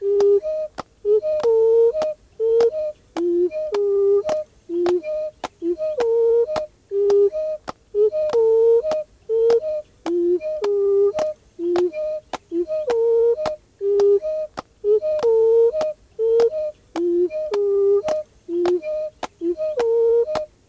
The original hindewhu sequence
with its ternary beat
sifflet3xbattue.aif